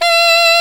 Index of /90_sSampleCDs/Roland L-CDX-03 Disk 1/SAX_Alto Short/SAX_Pop Alto
SAX D#4 S.wav